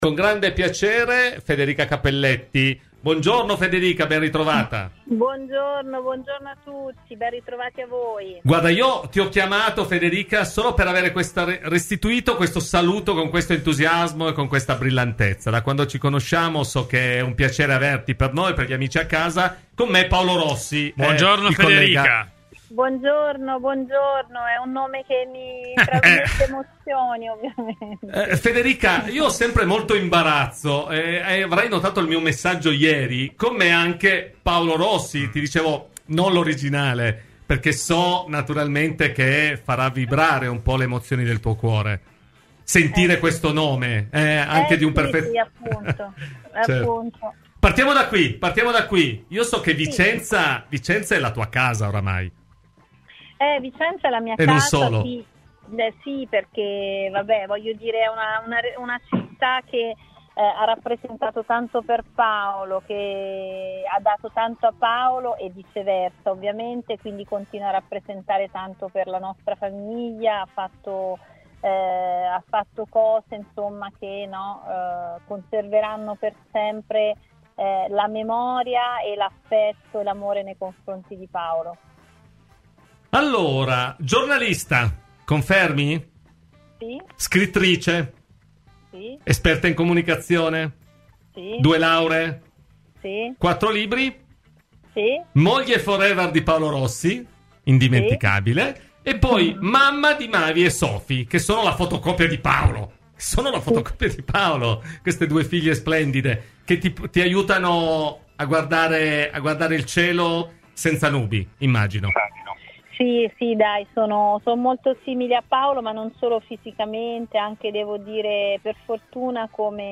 Ospite di "Cose di Calcio" su Radio Bianconera , la presidente della divisione calcio femminile FIGC Federica Cappelletti ha parlato della crescita del movimento in Italia, ma anche del tributo che Vicenza ha voluto dedicare al marito scomparso, l'indimenticato e indimenticabile Paolo Rossi: “ Vicenza è una città che ha dato tanto a Paolo e continua a rappresentare tanto per la nostra famiglia .